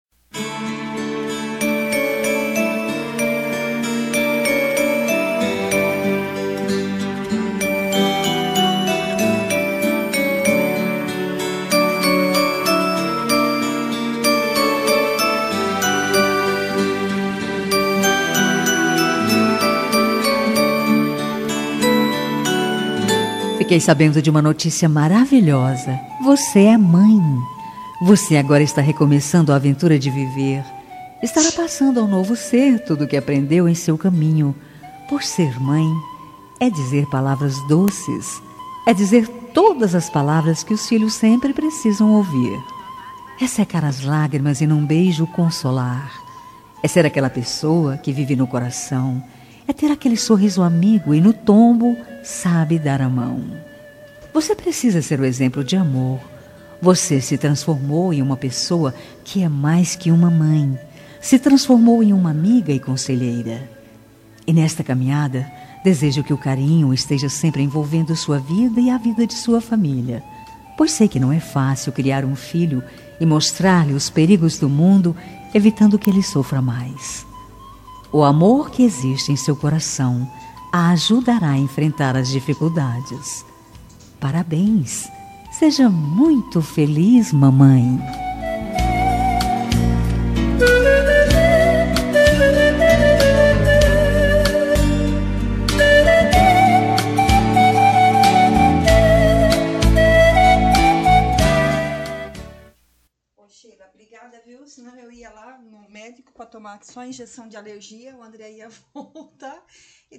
Telemensagem de Maternidade – Voz Feminina – Cód: 6614